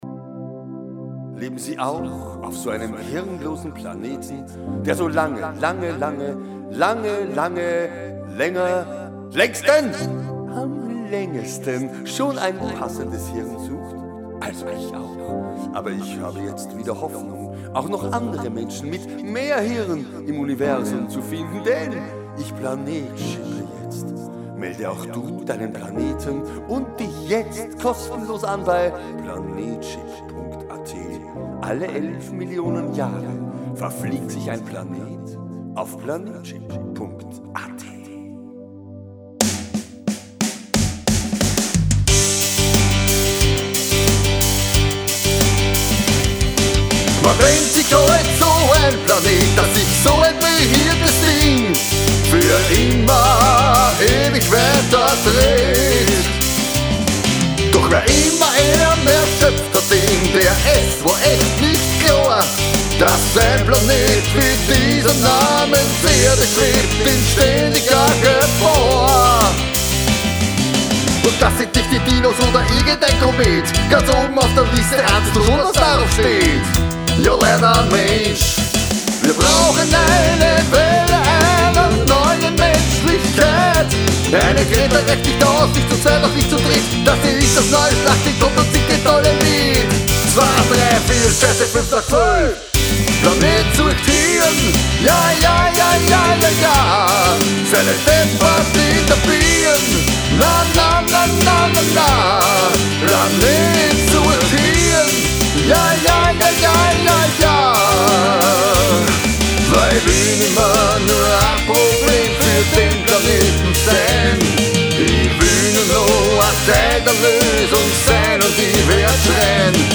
PB und GESANG NEU JULI 2021